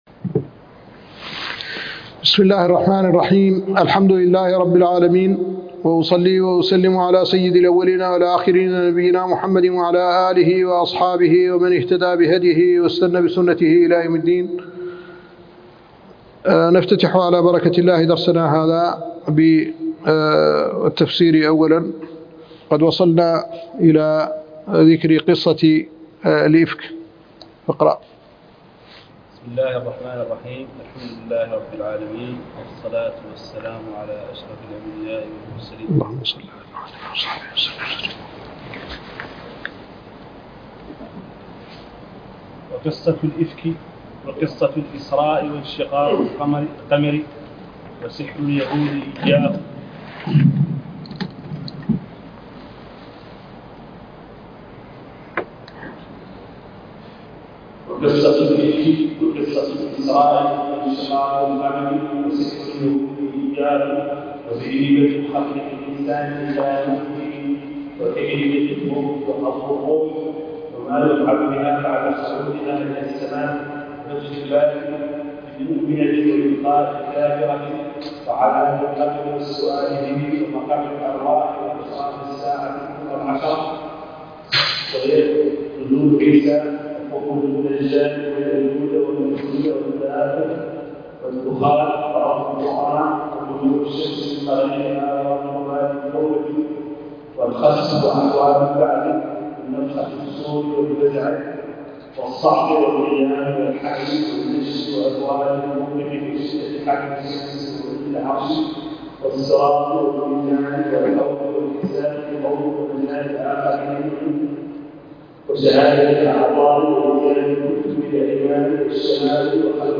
الدرس الرابع عشر من الإكليل